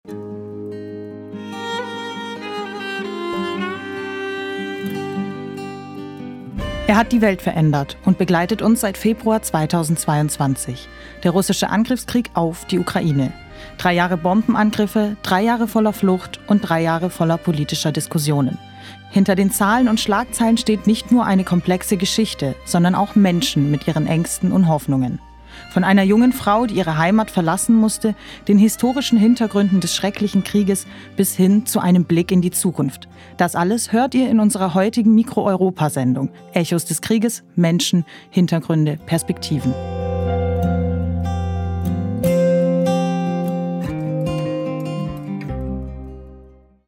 Teaser_591.mp3